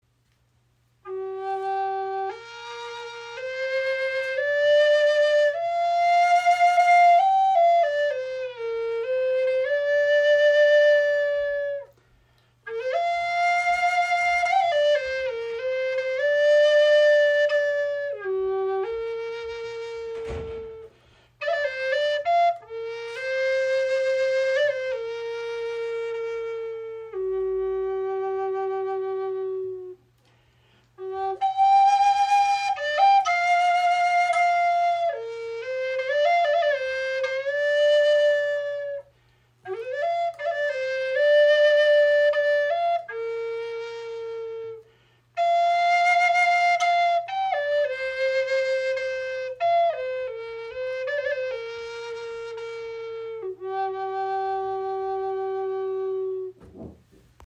Red Tail Hawk Flöte in G-Moll aus Spanish Cedar – warmer, kraftvoller Klang.
• Icon Satter, beruhigender Klang in G-Moll – vielseitig einsetzbar
Gefertigt aus einem Stück Spanish Cedar entfaltet sie einen warmen, erdigen Klang mit klaren Höhen.
Sie wird aus einem einzigen Stück heimischem Spanish Cedar gefertigt, einem weichen Tonholz mit ausdrucksstarkem Klangprofil: warme Höhen, klare Mitten und tragende Tiefen.
High Spirits Flöten sind Native American Style Flutes.